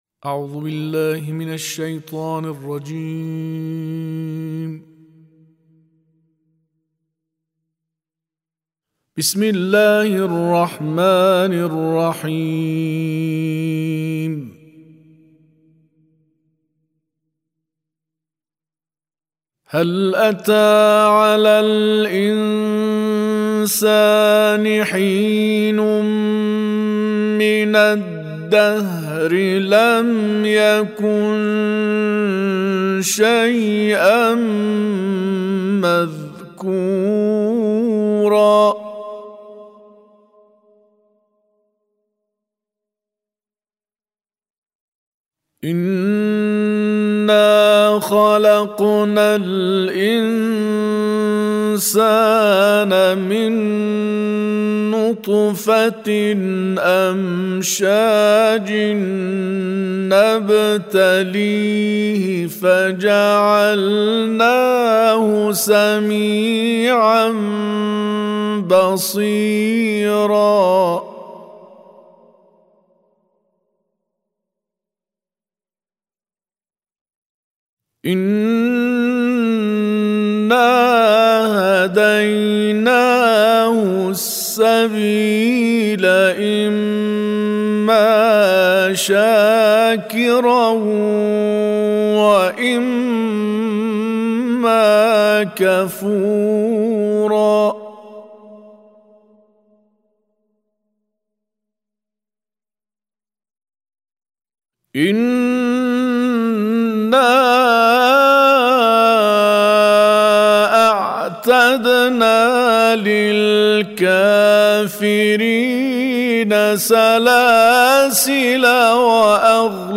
سورة الإنسان و سورة الكوثر - سجلت في استديو دار السيدة رقية (ع) بمناسبة ميلاد السيدة فاطمة الزهراء(ع) لحفظ الملف في مجلد خاص اضغط بالزر الأيمن هنا ثم اختر (حفظ الهدف باسم - Save Target As) واختر المكان المناسب